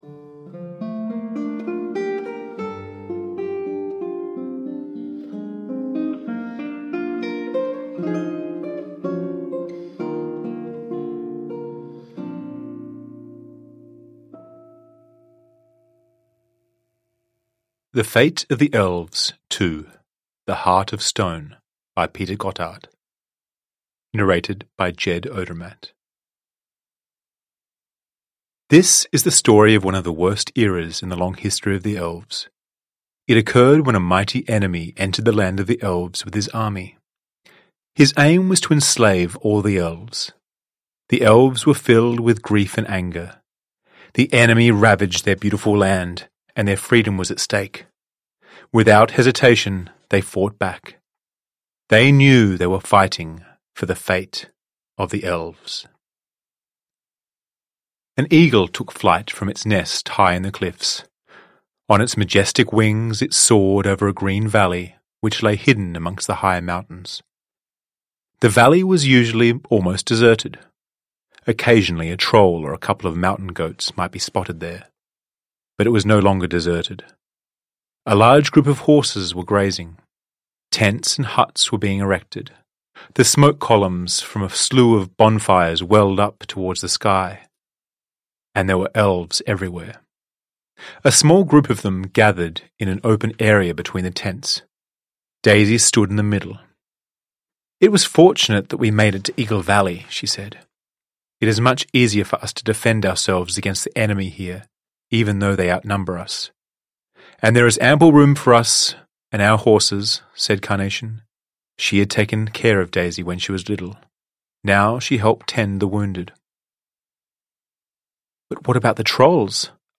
The Fate of the Elves 2: The Heart of Stone / Ljudbok